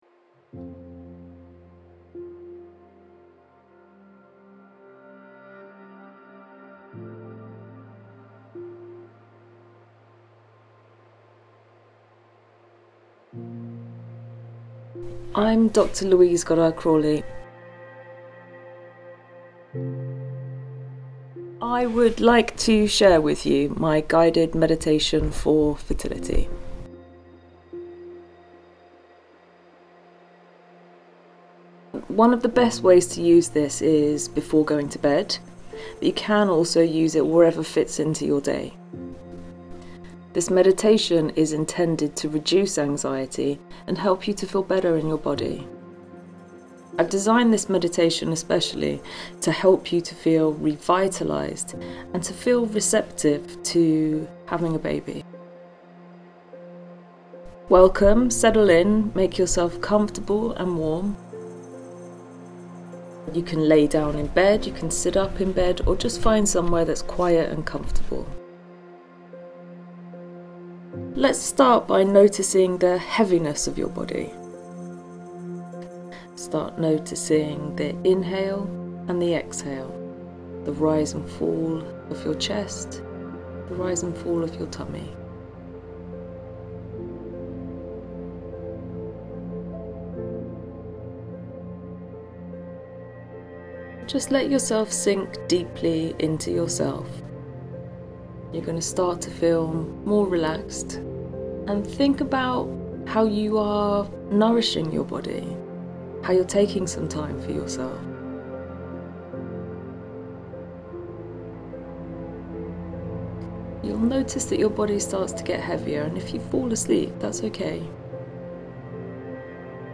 In the meantime, I've recorded a Fertility Meditation for you to listen to online or to download.